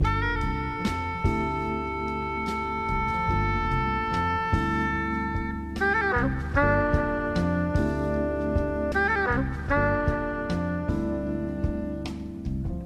razor 3 trumpet 74.wav